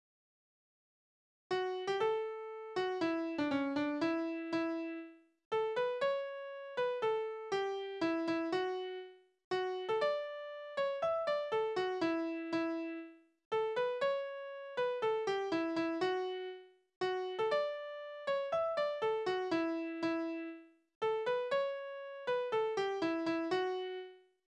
Elegien: Anklage des treulosen Liebsten
Tonart: D-Dur
Tonumfang: kleine Dezime
Besetzung: vokal